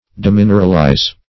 demineralize - definition of demineralize - synonyms, pronunciation, spelling from Free Dictionary